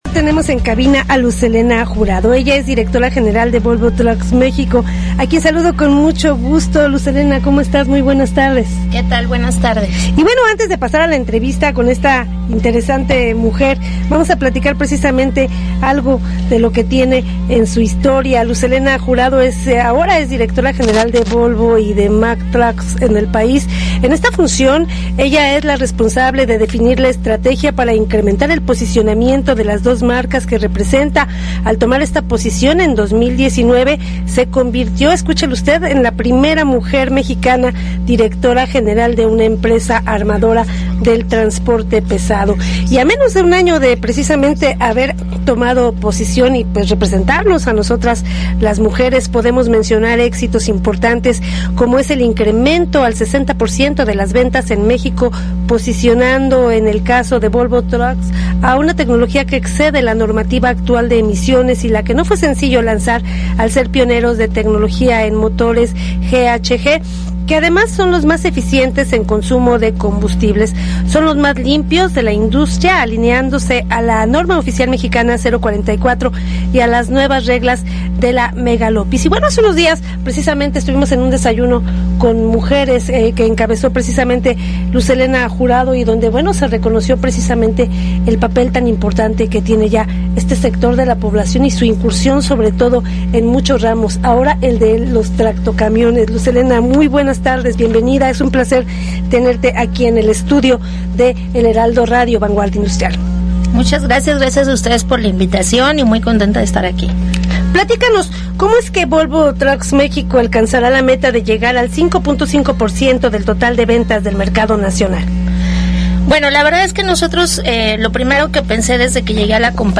En entrevista en el programa de Vanguardia Industrial Radio